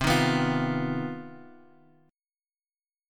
CmM11 Chord
Listen to CmM11 strummed